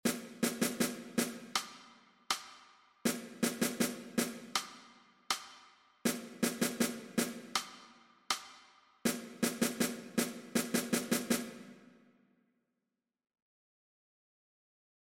O terceiro corte será co que remate batucada. Nel engadimos letra para que o día da sesión de baile berre todo o colexio con nós.